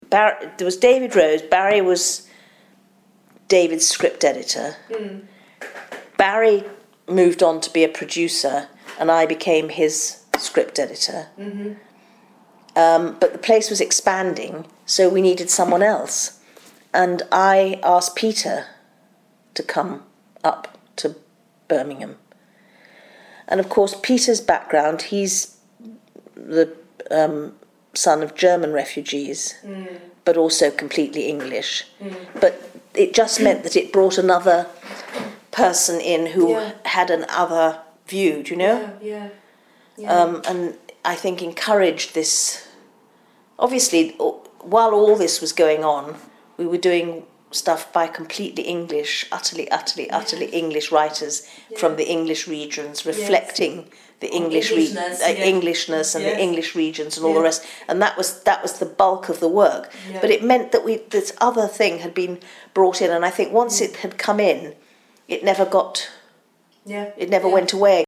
including extracts from an interview